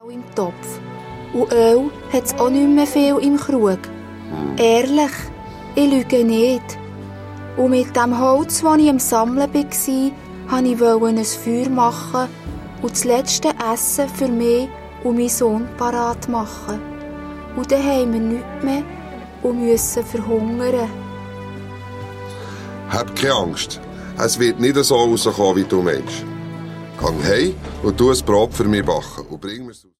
Hörspiel - Album